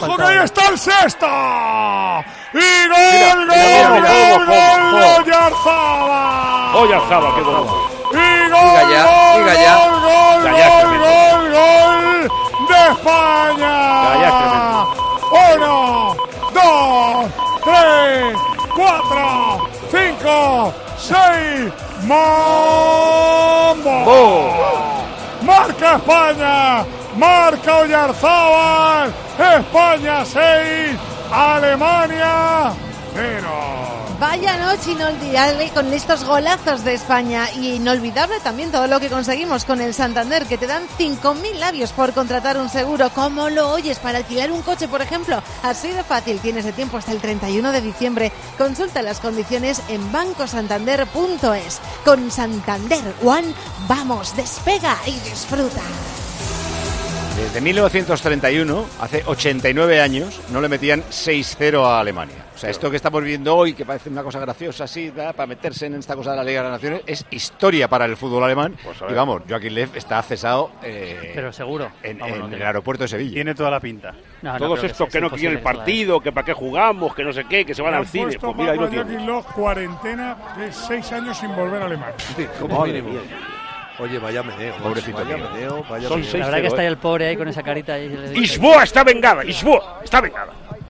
- ESCUCHA LOS SEIS GOLES DEL PARTIDO NARRADOS POR MANOLO LAMA: